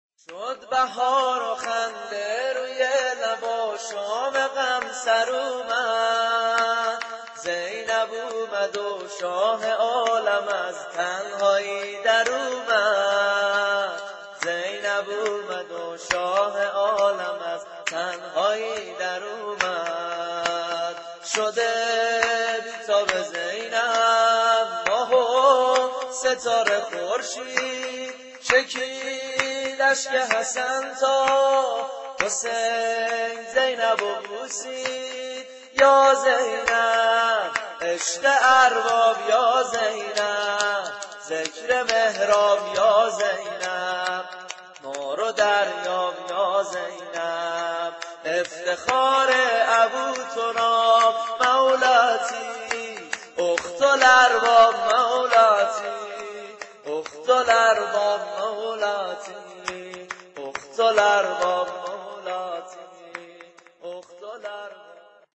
سرود میلاد